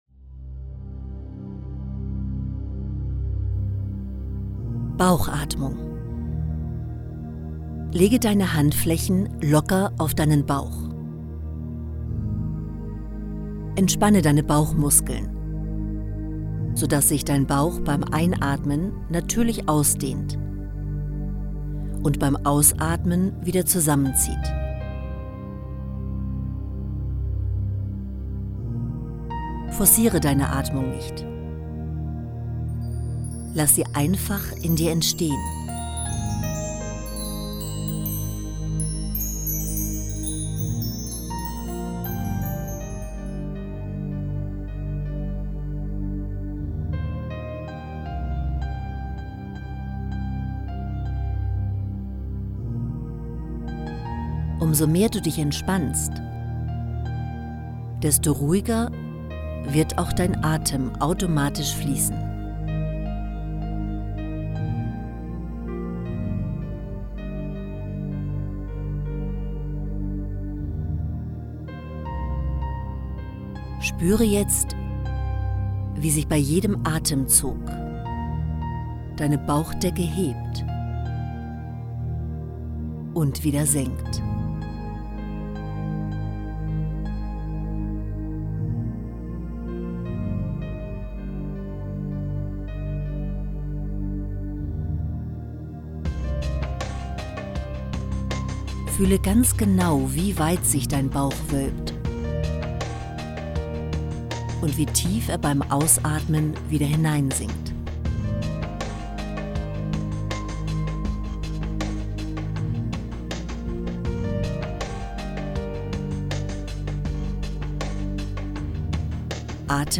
Podcast #3 – Angeleitete Atem-Meditation